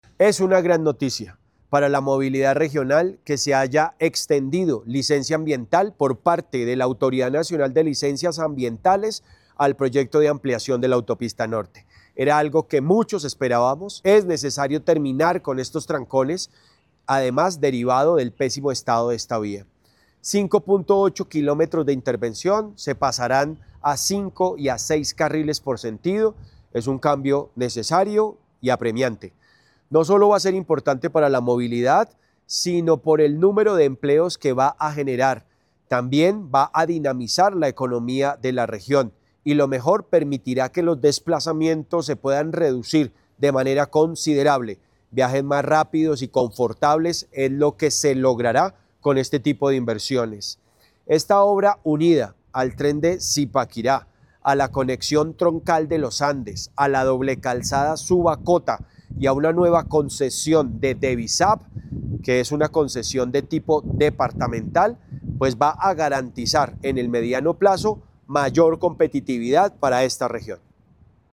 El gobernador de Cundinamarca también se manifestó. Escuche sus palabras en el audio de esta nota.
Full-Gobernador-de-Cundinamarca_-Jorge-Emilio-Rey-Angel-.mp3